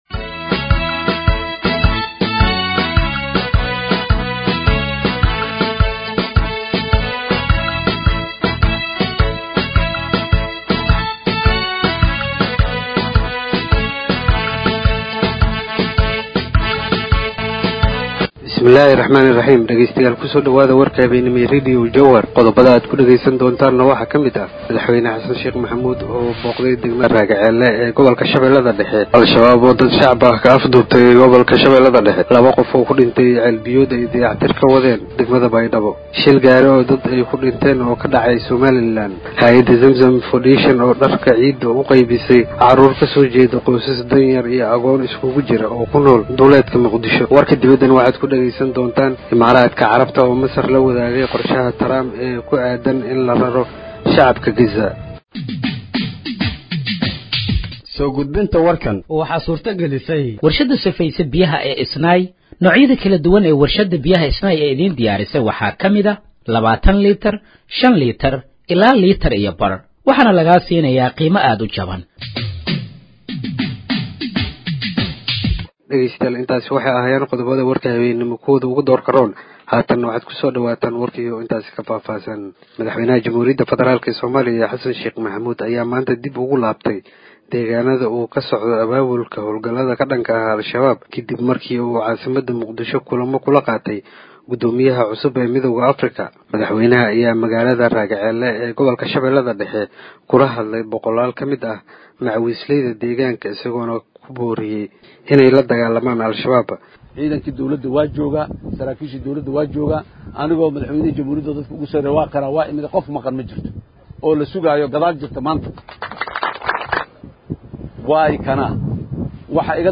Dhageeyso Warka Habeenimo ee Radiojowhar 25/03/2025